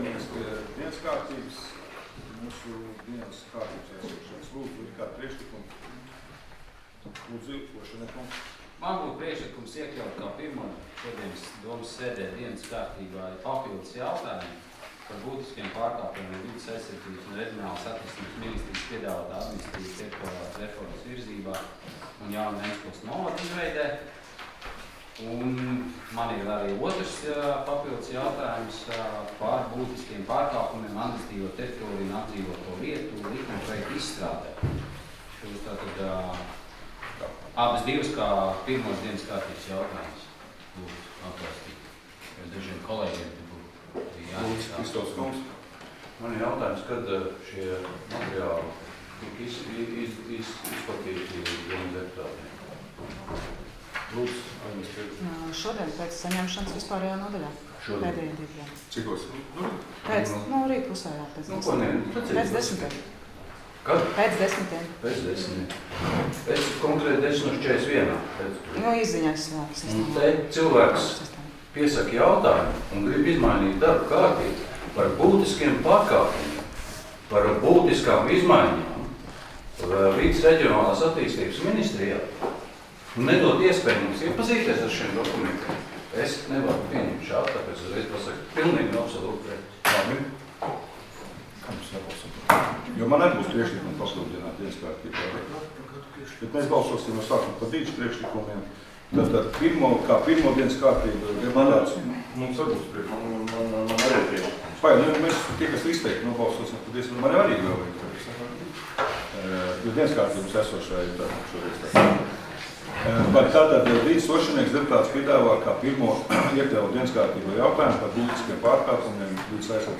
Domes sēdes 13.09.2019. audioieraksts